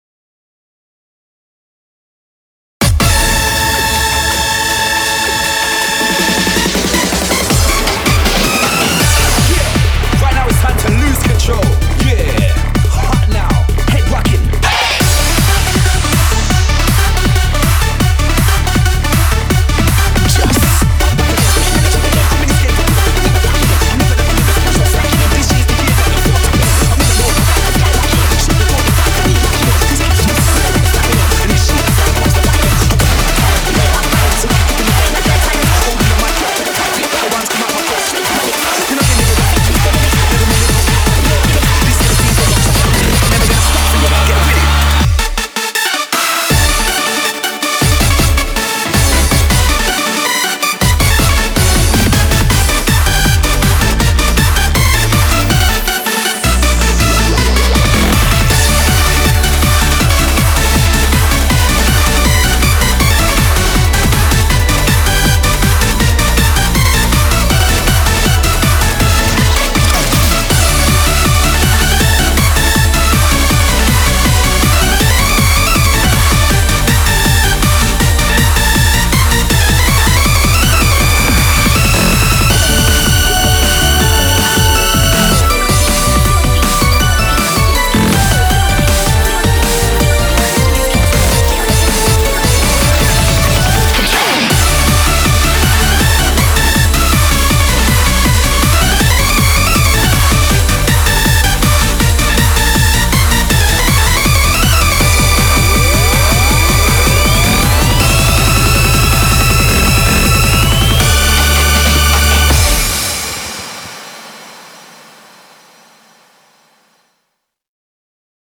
BPM160
Audio QualityPerfect (High Quality)